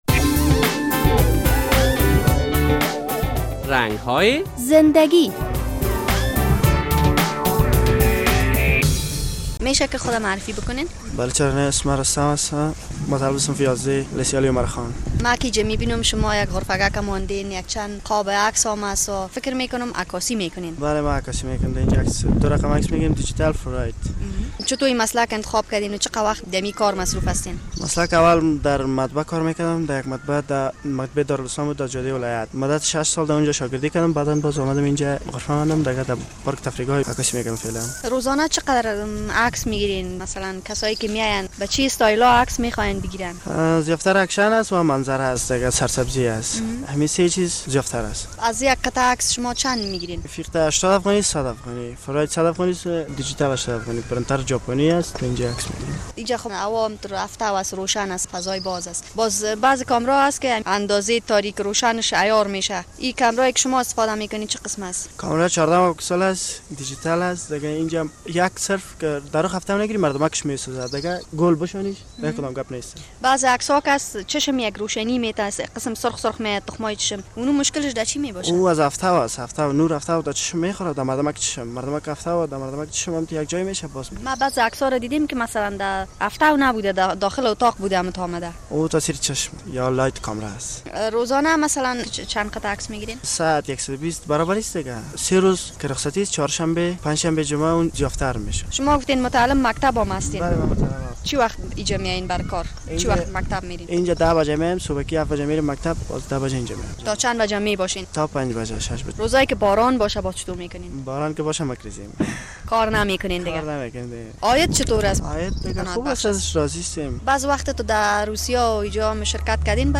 جوانان افغان با شوق و علاقه خاص که به آموختن دارند برعلاوه مشکلات اقتصادی هدف خود را تعقیب می کنند، در این برنامه با یک جوان افغان که یک غرفه عکاسی دارد و مصارف خود را بدست می آورد مصاحبه کرده ایم: